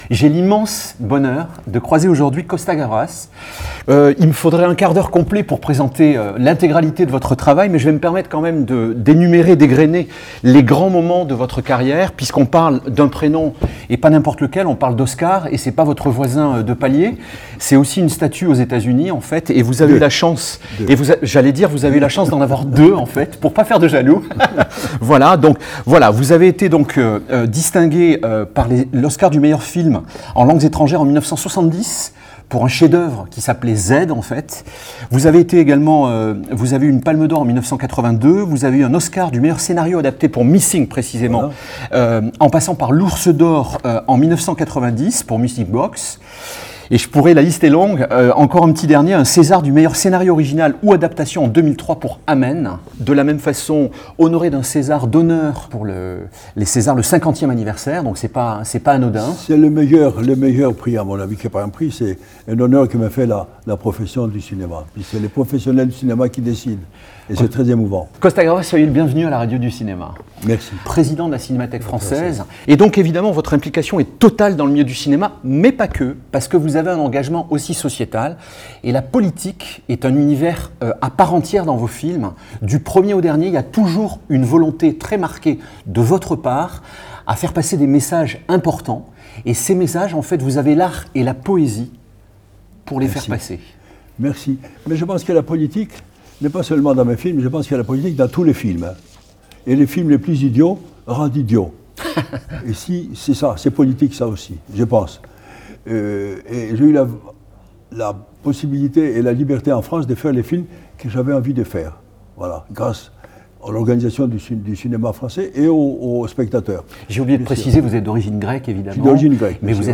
interview: Costa-Gavras, Kad Merad et Denis Podalydès : la fin de vie sous le regard du cinéma